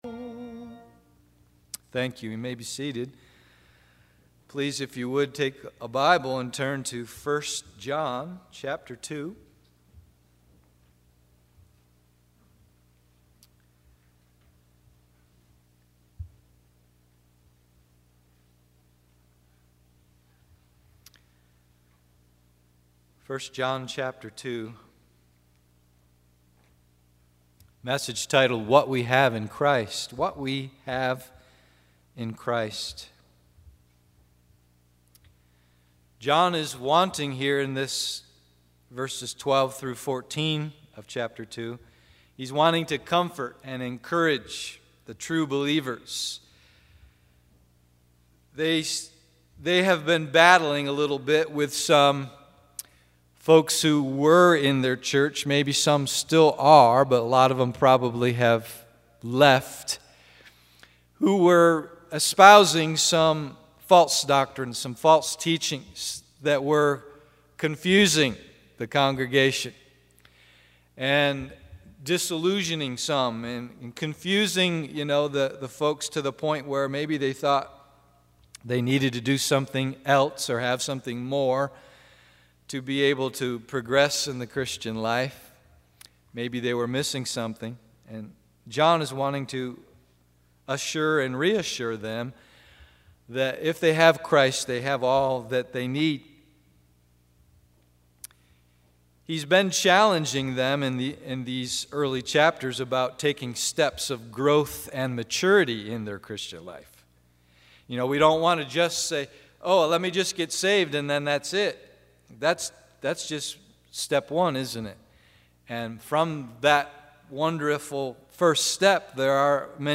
What We Have in Christ AM Service